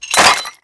wrench_hit_tile2.wav